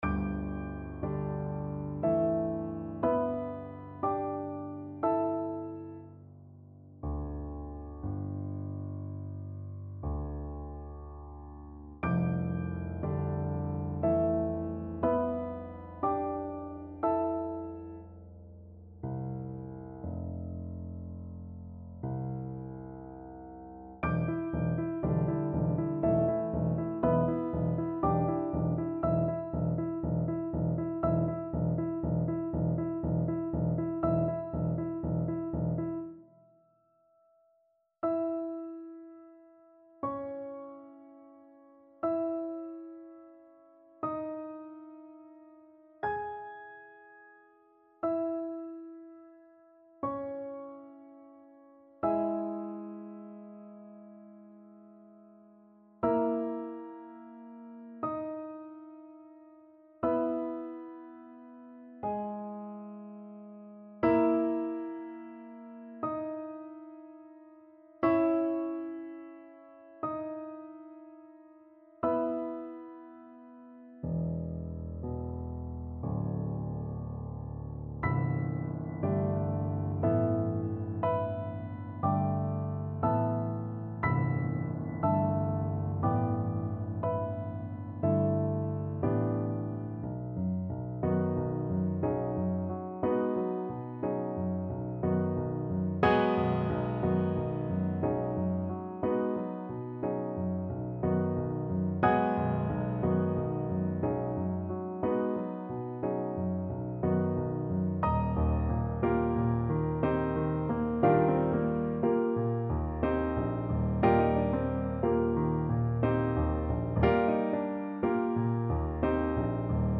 Piano version
No parts available for this pieces as it is for solo piano.
6/4 (View more 6/4 Music)
Piano  (View more Advanced Piano Music)
Classical (View more Classical Piano Music)